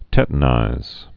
(tĕtn-īz)